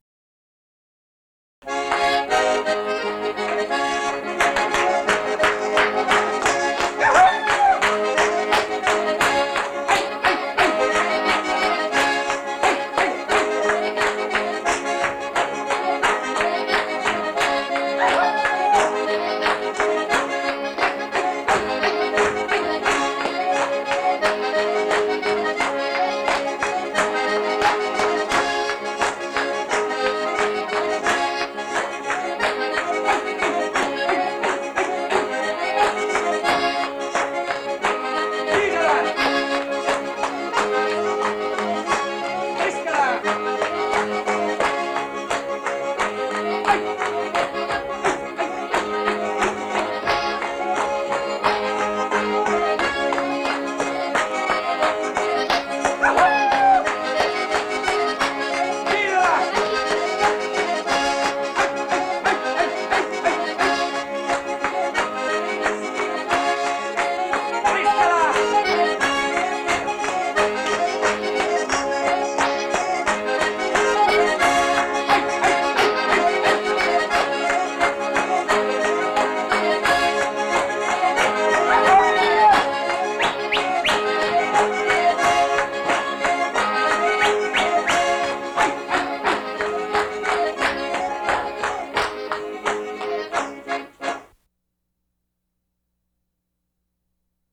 8 - accordion player in San Arsenio, Salerno - Ballinello (Tarantella).mp3